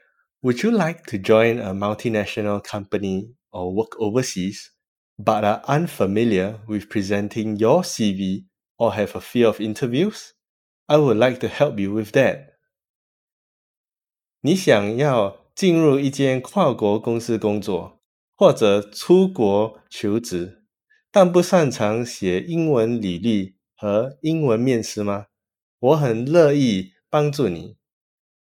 ※ 因為新加坡人講話的腔調比較特別，你需要「全英文模擬面試 / 全英文對話練習」的話，可以先聽聽以下我老公的簡短錄音（英文+中文），看能不能接受，想知道我老公長怎樣的則可以參考一下我的 Facebook 相片。